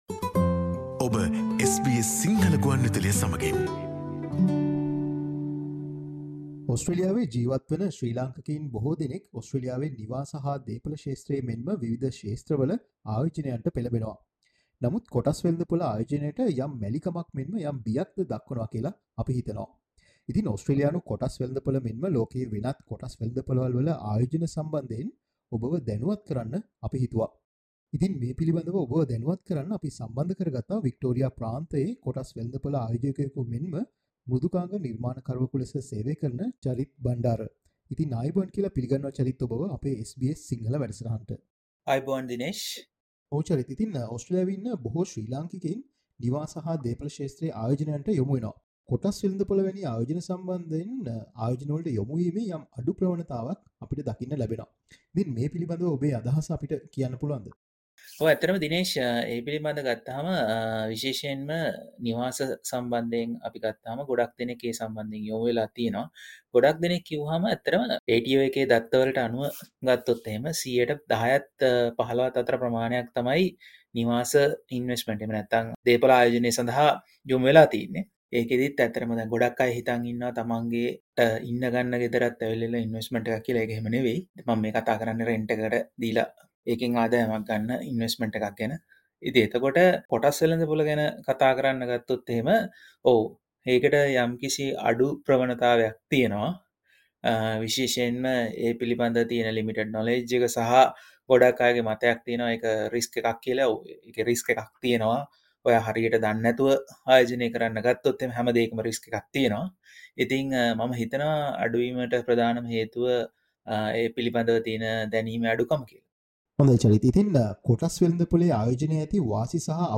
Listen to the SBS Sinhala interview for more information and tips on becoming a successful stock market investor.